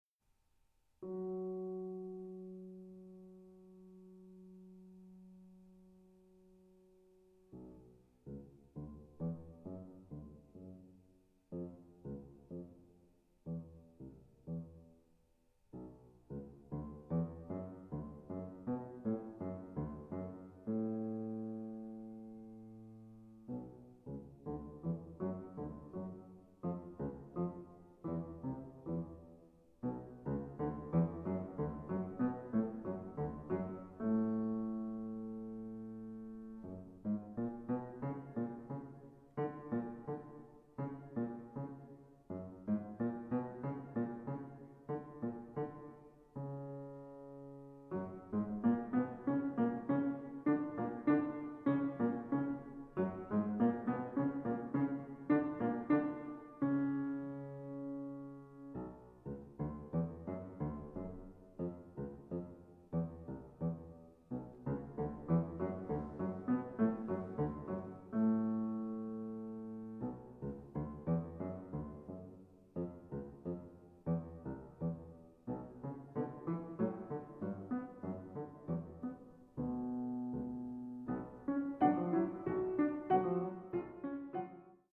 arr. for piano 4 hands by composer
(Alla marcia e molto marcato)